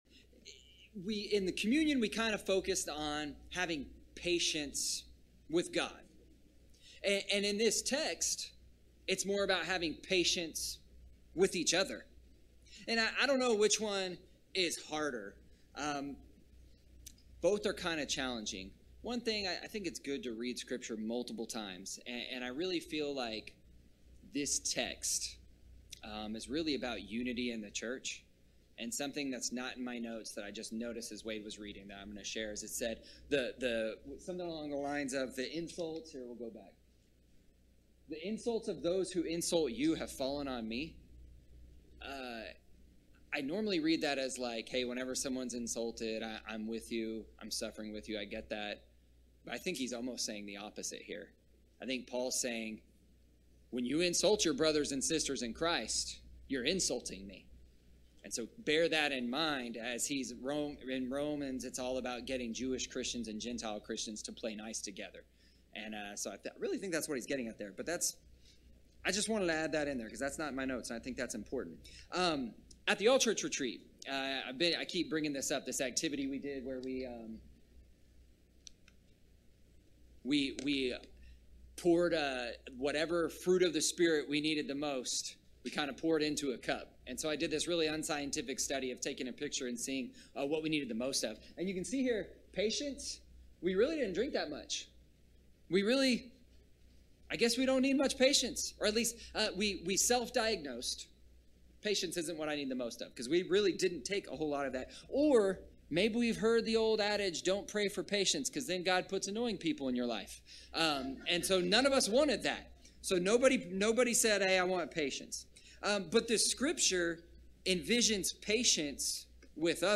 This week's bulletin - 7/16/2023 More from the series: Fruits of the Spirit series ← Back to all sermons